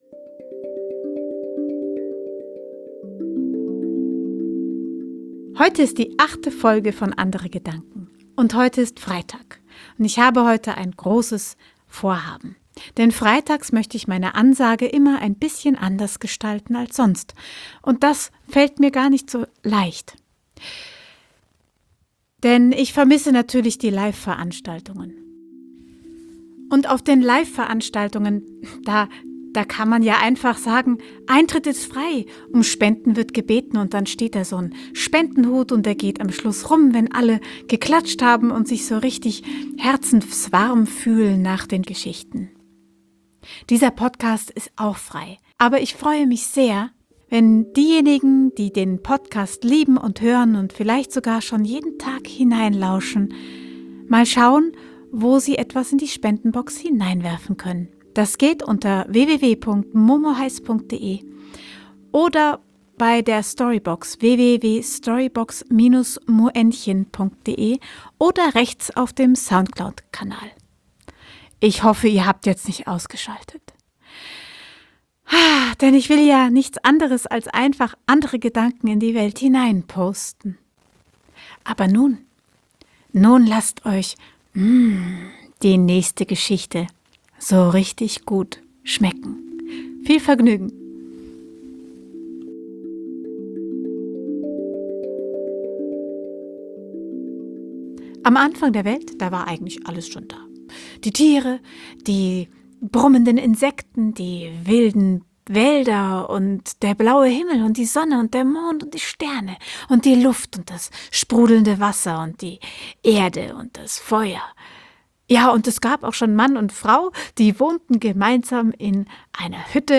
frei erzählte Geschichte